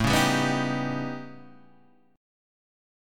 A 9th Flat 5th